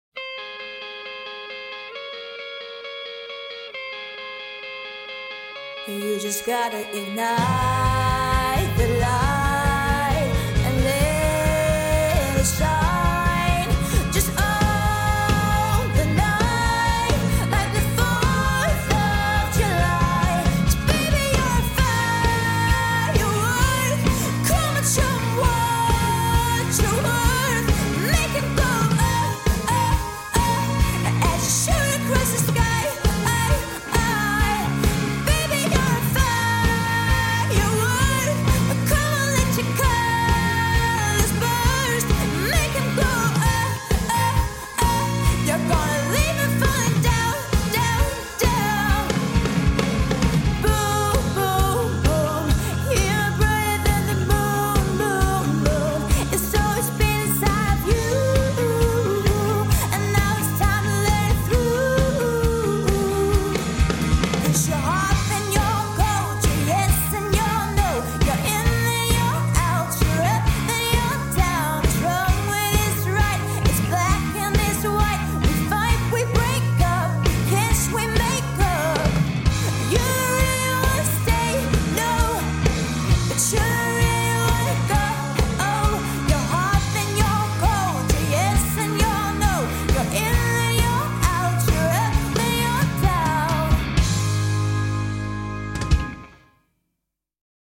leverer dem som rock-sange